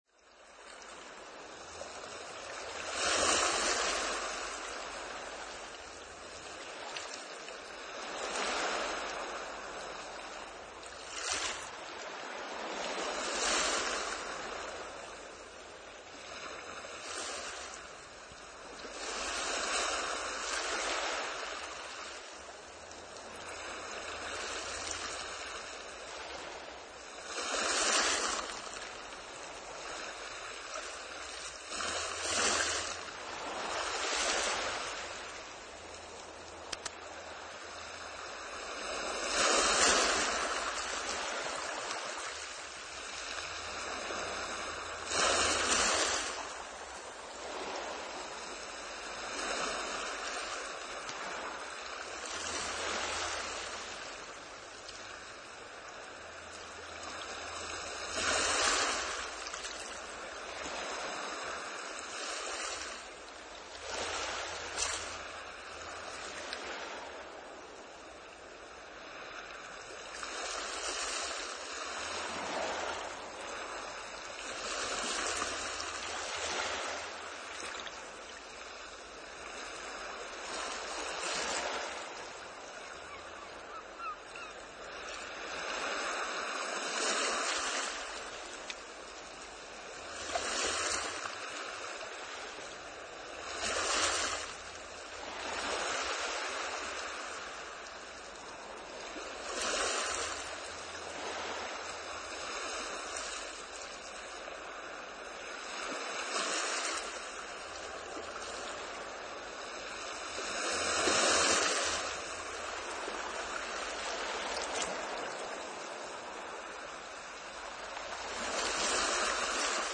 Das leise Plätschern der Wellen.
Für einen ersten Versuch können Sie diesen komprimierten Ausschnitt aus unserer eigenen CD verwenden:
Meeresrauschen-Bali-kurz.mp3